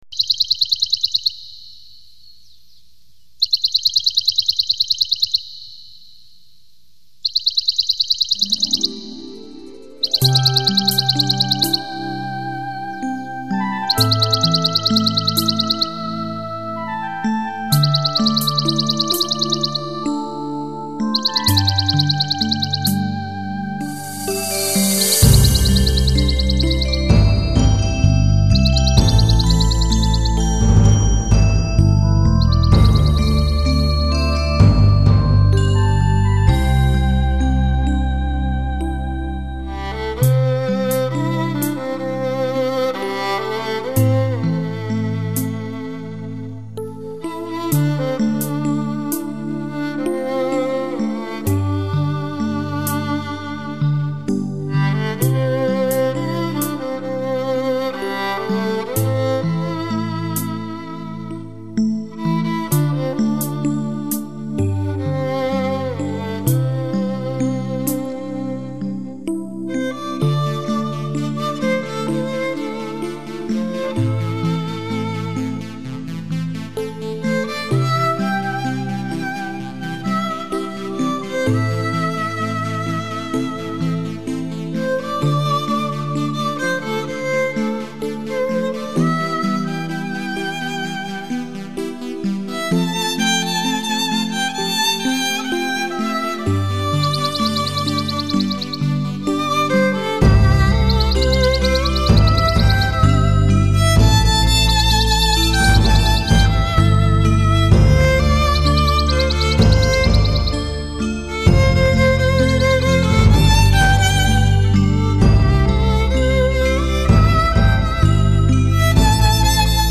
纯音乐
古筝